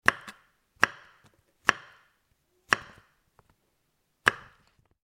Звуки грибов
Нарезка грибов острым ножом